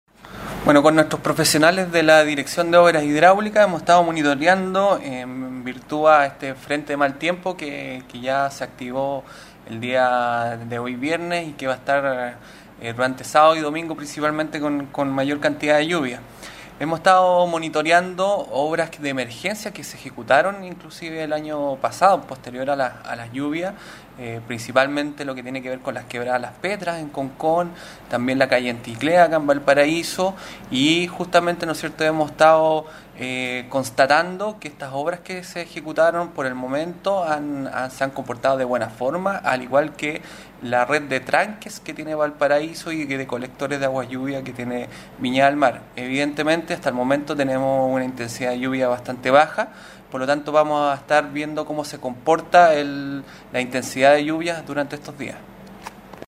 El seremi de Obras Públicas, Miguel Saavedra explicó que además está en ejecución un contrato de limpieza y mantención de la red de aguas lluvia de Valparaíso, que incluye trabajos en tranques y colectores.